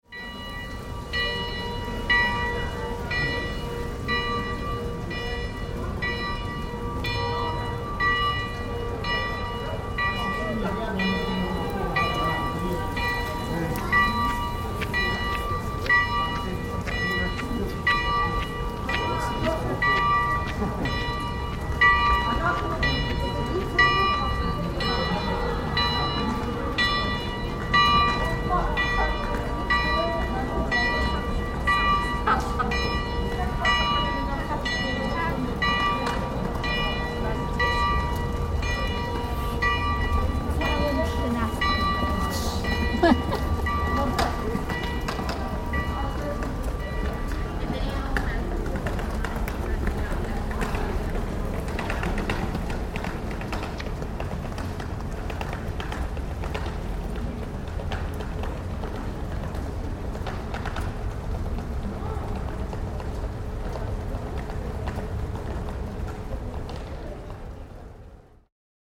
This soundscape from Kraków’s Old Town reveals the heartbeat of a city steeped in history and animated by modern energy.
Echoes of tradition weave seamlessly with the rhythm of everyday life: ancient clock towers toll above the hum of trams, mingling with the soft shuffle of cars and the lively chatter of travelers and locals passing through narrow streets. Voices carry through the air, layering over footsteps and faint bursts of activity that make the square a hub of movement and connection.
This recording offers a glimpse into its living narrative - a harmony of sounds both historic and immediate, embodying the spirit of a city that celebrates its legacy while moving ever forward.